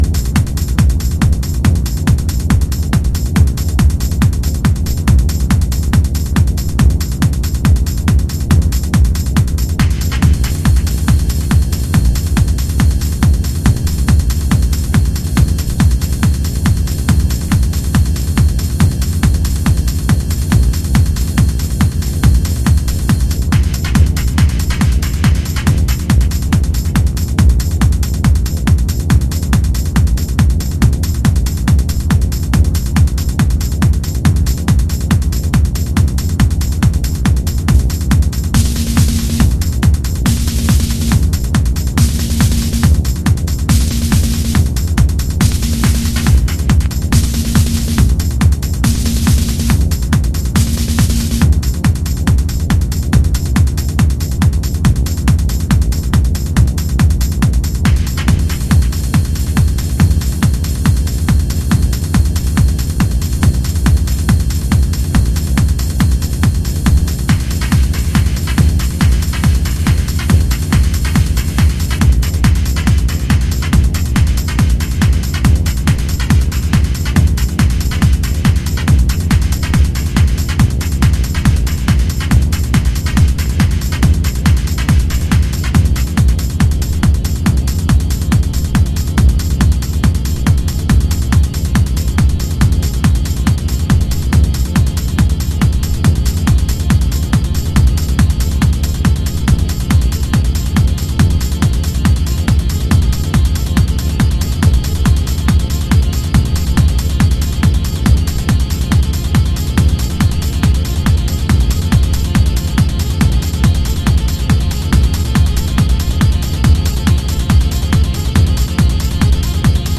Early House / 90's Techno
剥き出しのうねりと不穏なパッドシンセで魅せてくれる